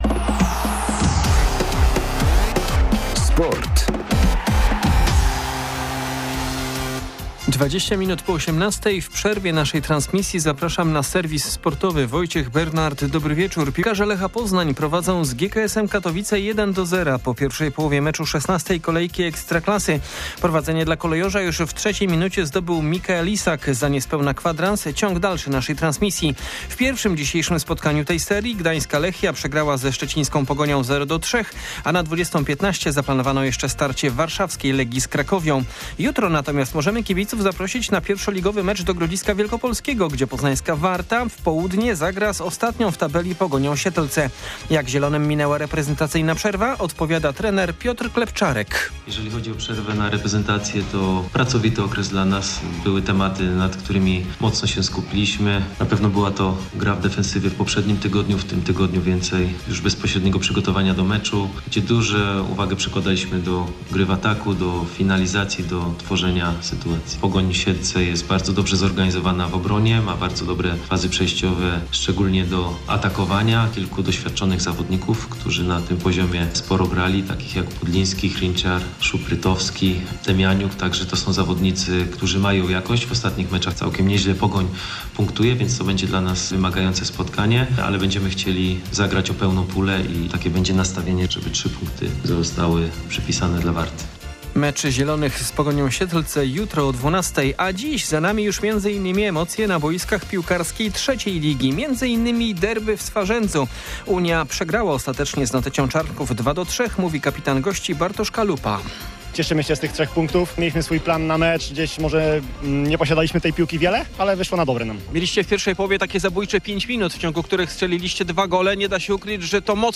23.11.2024 SERWIS SPORTOWY GODZ. 18:20
Serwis tym razem w przerwie naszej transmisji z meczu piłkarzy Lecha, ale poza Kolejorzem także sporo o niedzielnym spotkaniu pierwszoligowej Warty Poznań oraz trzecioligowych derbach Wielkopolski. Poza tym informacje z zimowych aren sportowych i zapowiedź ciekawych wydarzeń na parkietach piłki ręcznej.